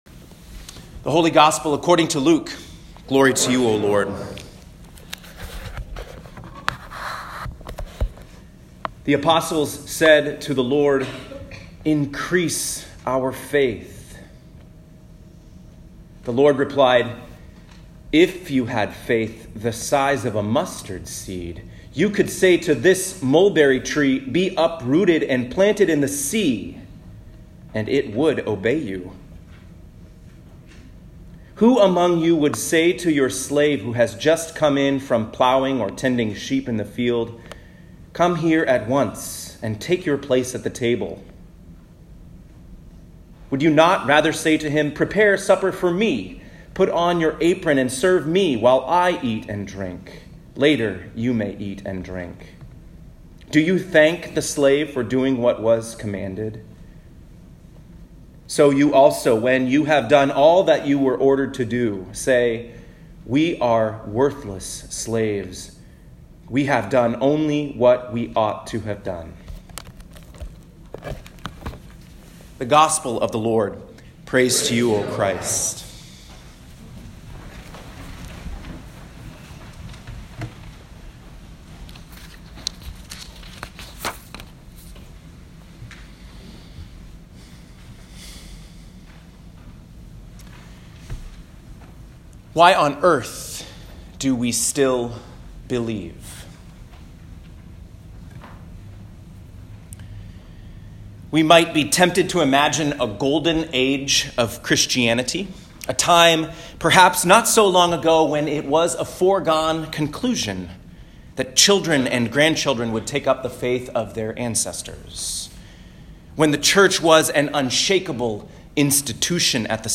Seventeenth Sunday after Pentecost, Year C (10/6/2019)
Seventeenth Sunday after Pentecost, Year C (10/6/2019) Habakkuk 1:1-4; 2:1-4 Psalm 37:1-9 2 Timothy 1:1-14 Luke 17:5-10 Click the play button to listen to this week’s sermon.